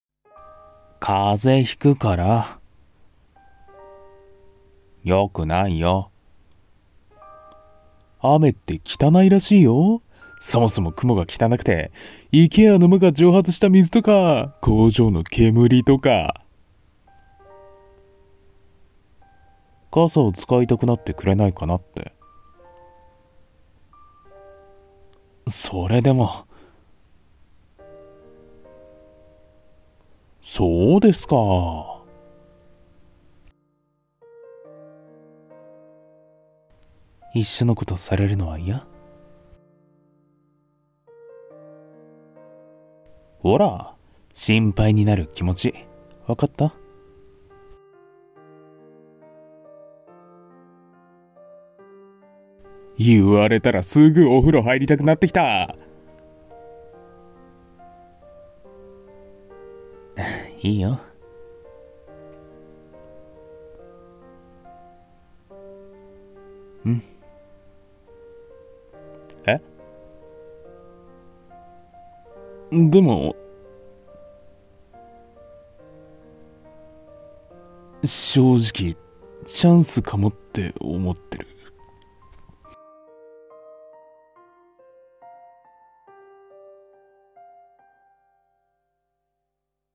雨、終わり、始まり。【二人声劇】 演◆